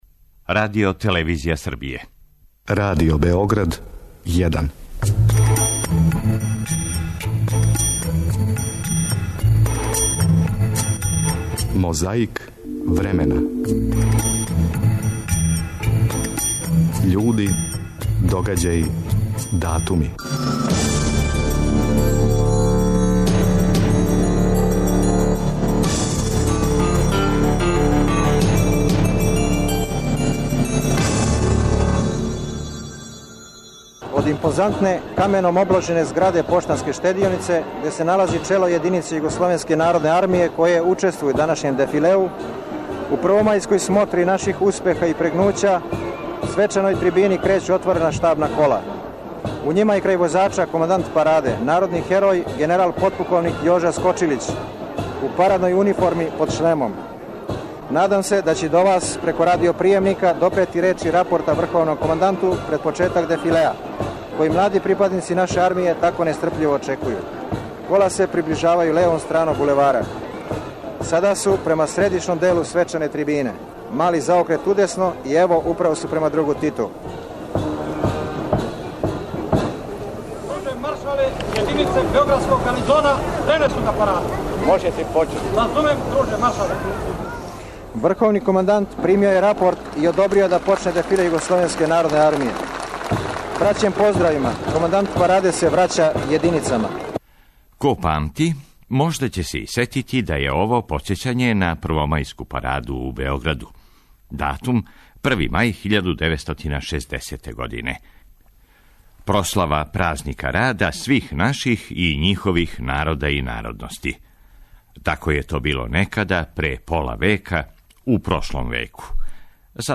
У Мозаику времена и даље траје седница Скупштине републике Српске која је одржана 5. маја 1993. године на којој говори Радован Караџић.
Подсећа на прошлост (културну, историјску, политичку, спортску и сваку другу) уз помоћ материјала из Тонског архива, Документације и библиотеке Радио Београда.